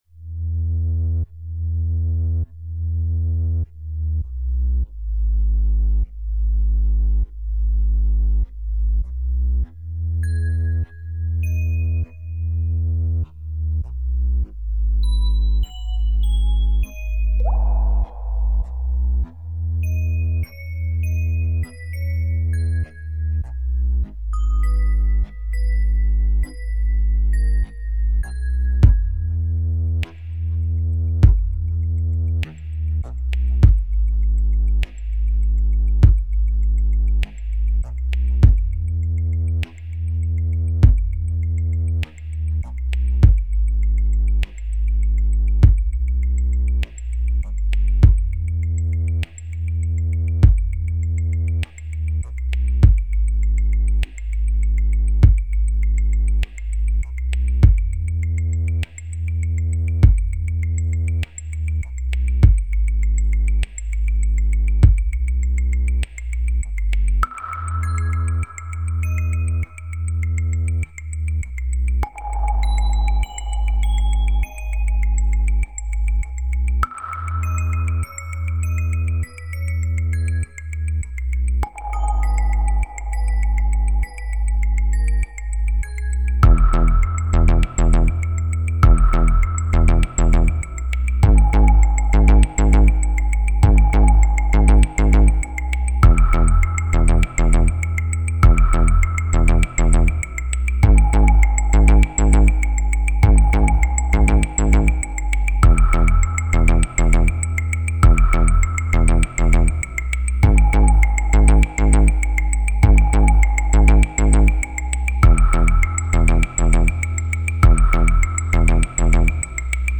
the video was not kind to the sound in terms of compression. full quality version as just a mp3
headphones or proper speakers required due to heavy use of bass frequencies.